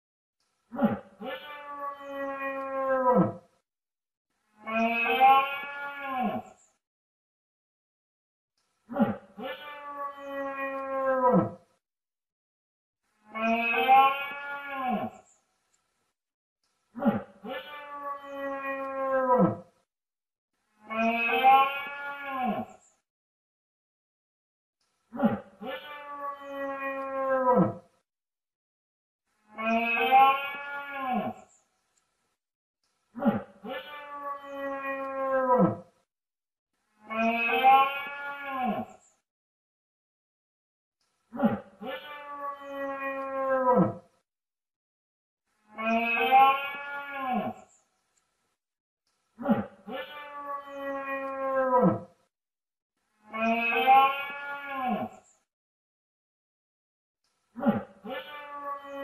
Efeito Sonoro / Cervo sound effects free download